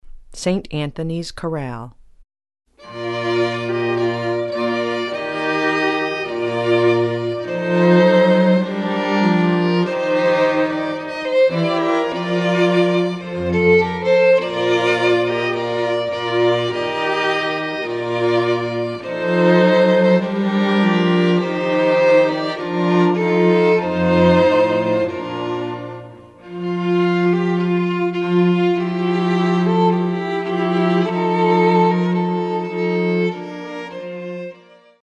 The Music of The Woodvale String Quartet
In addition, we have numerous arrangements, for string quartet and singer, of the most common pieces of music used in the Catholic ceremony and Mass.